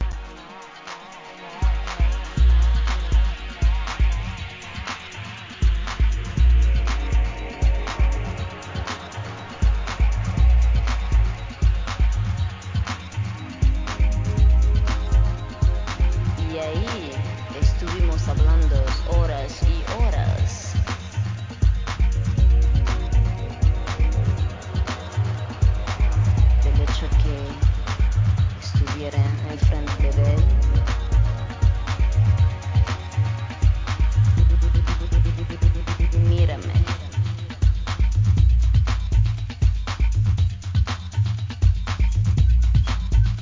UKブレイクビーツ